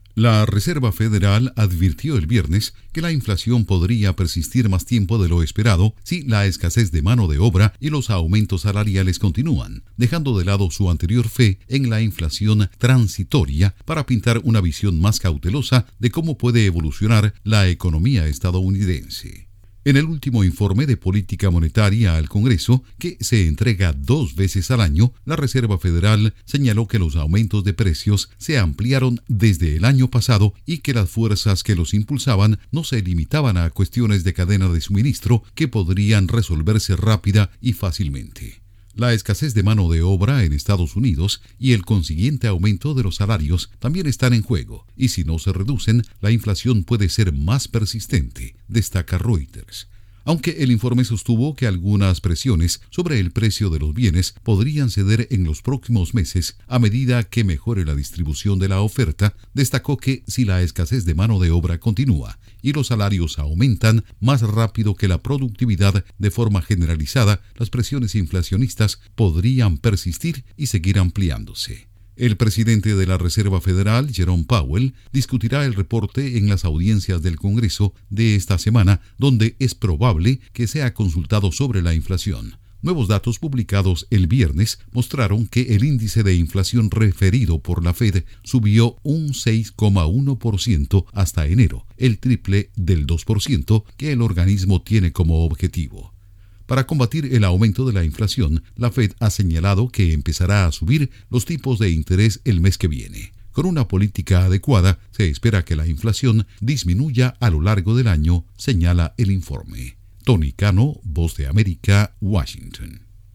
Informe de la Fed dice que trabajo y salarios podrían impulsar una inflación persistente. Informa desde la Voz de América en Washington